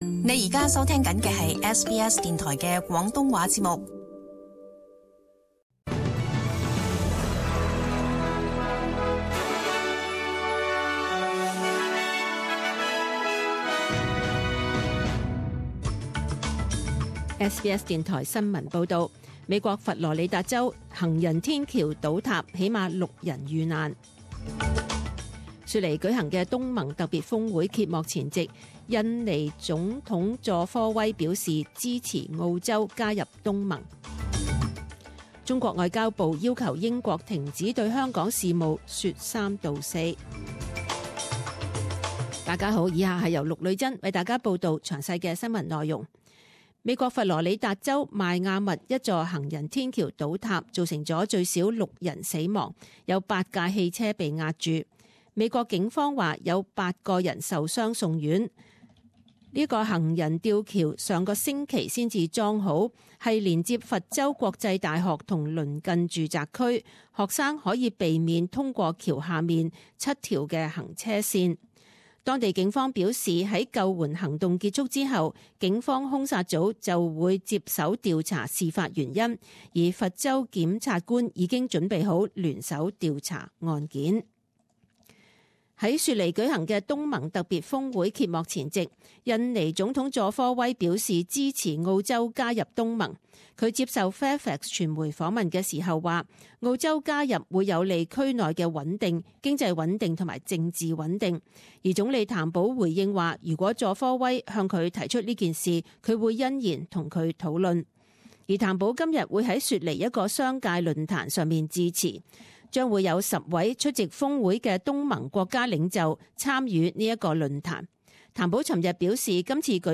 SBS中文新闻 （三月十六日）
请收听本台为大家准备的详尽早晨新闻。